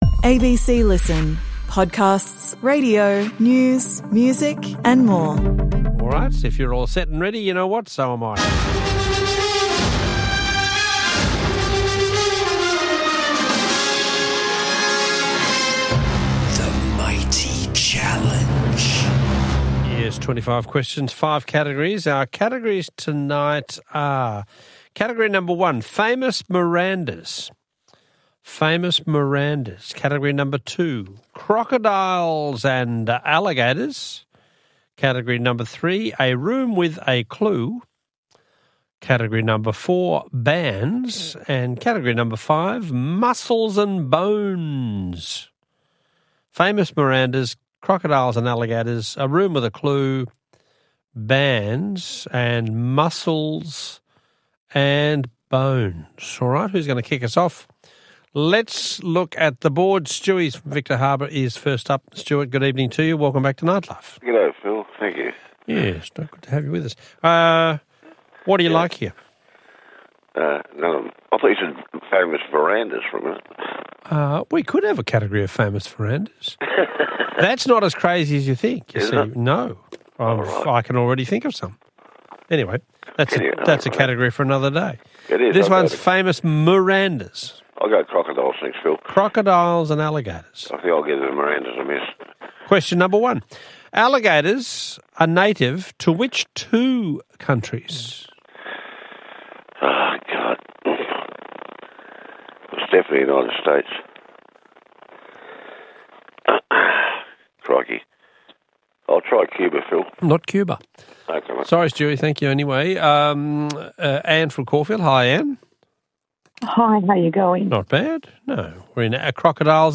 1 The Mighty Challenge Wednesday April 30th 2025 58:43 Play Pause 7h ago 58:43 Play Pause Play later Play later Lists Like Liked 58:43 Are you into your trivia? Calling all connoisseurs of cryptic to the only quiz played live, all around Australia.